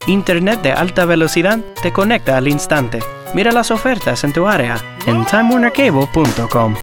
Adult, Young Adult
Has Own Studio
standard us
commercial
cool